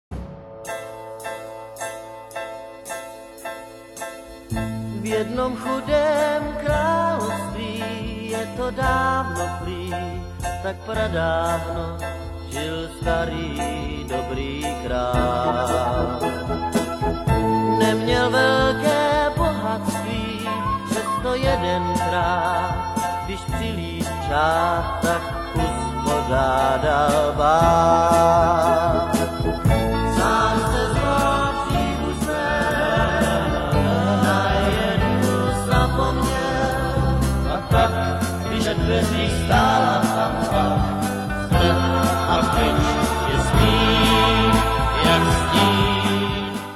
legenda českého rocku a popu.